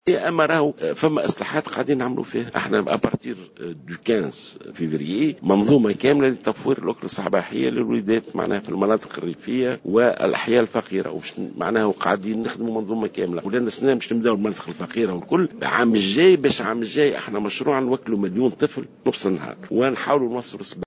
أعلن وزير التربية ناجي جلول في تصريح